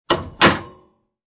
Descarga de Sonidos mp3 Gratis: puerta 9.
descargar sonido mp3 puerta 9